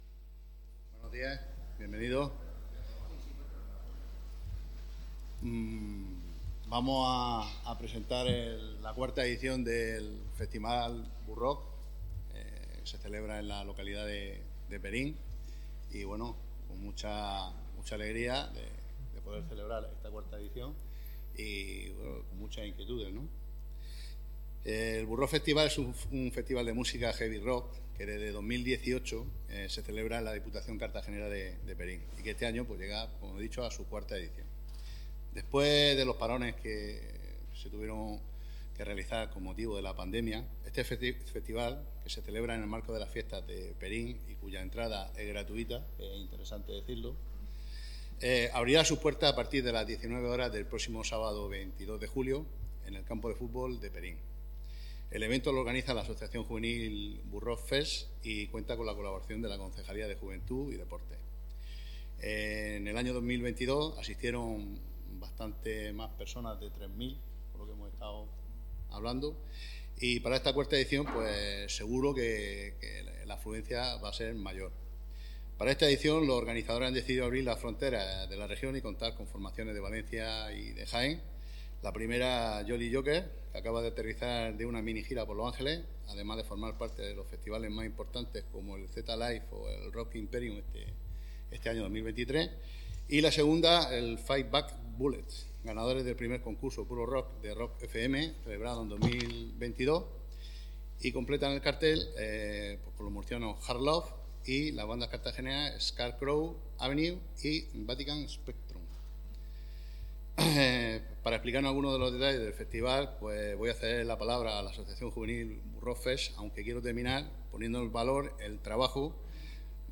Presentación Burrock Fest 2023